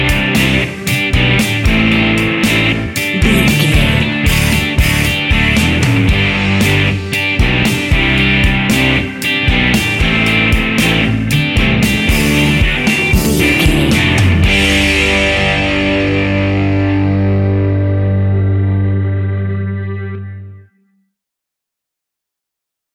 Uplifting
Ionian/Major
fun
energetic
acoustic guitars
drums
bass guitar
electric guitar
piano
organ